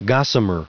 Prononciation du mot gossamer en anglais (fichier audio)
Prononciation du mot : gossamer